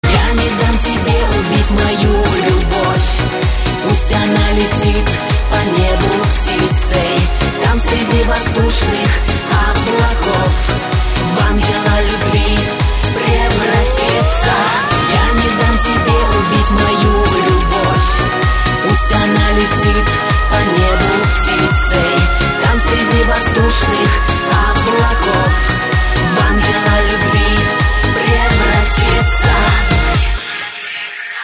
поп
мужской вокал
красивые
женский вокал
dance